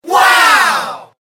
Звуки вау
Группа людей ваукнула